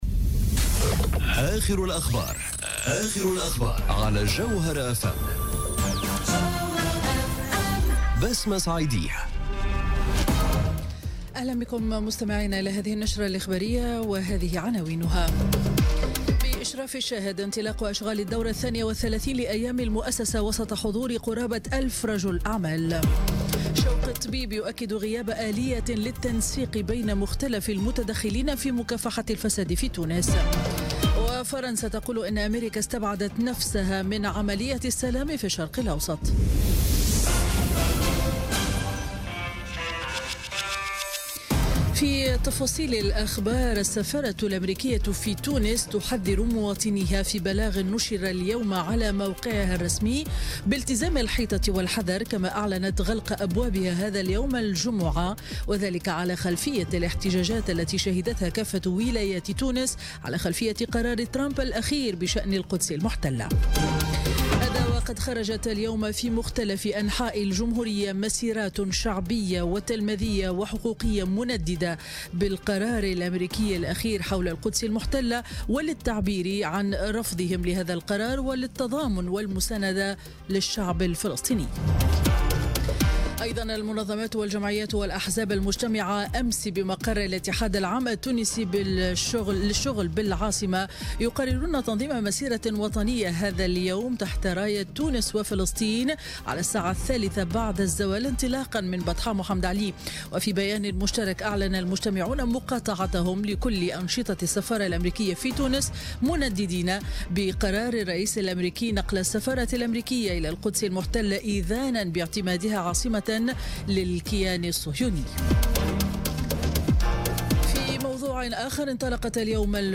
نشرة أخبار منتصف النهار ليوم الجمعة 8 ديسمبر 2017